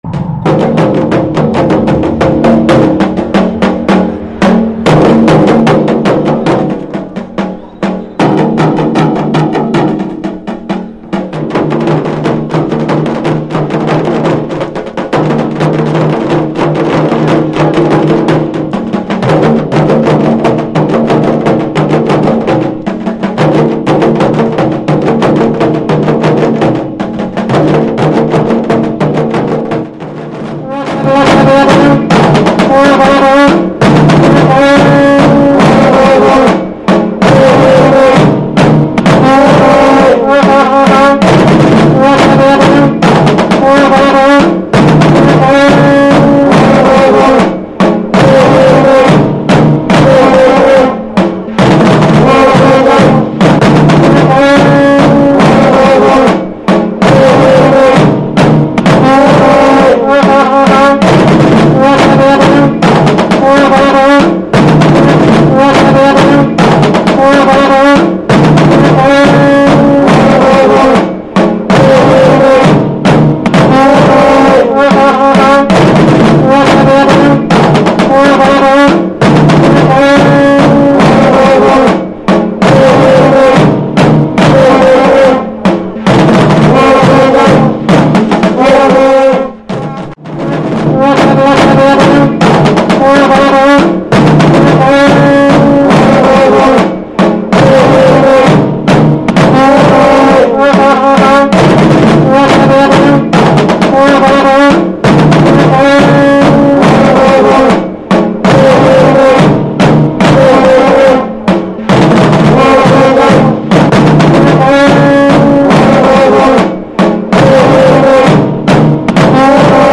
naghare.mp3